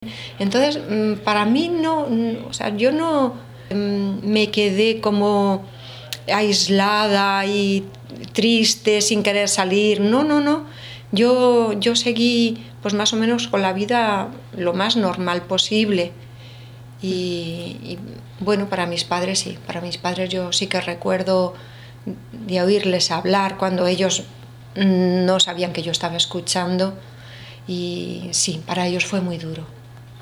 Y le tiembla la voz mientras entrelaza los dedos de sus manos.